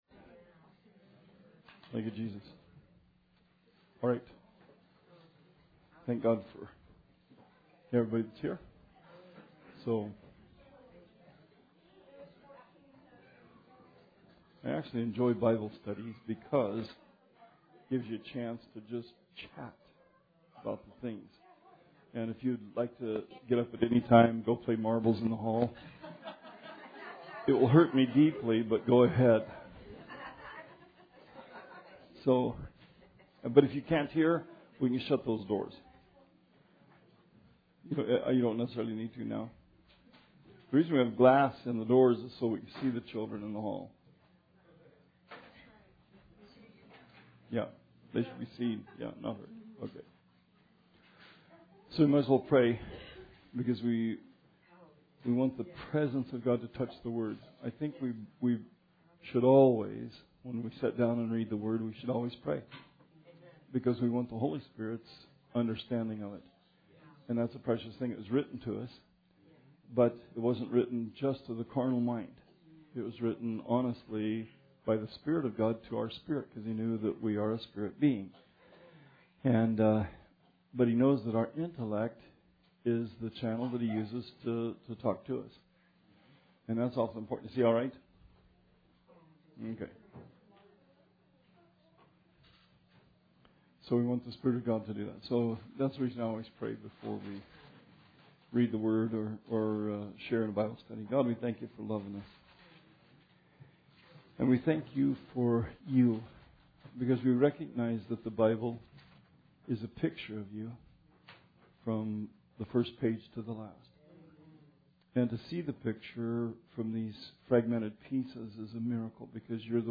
Bible Study 12/31/16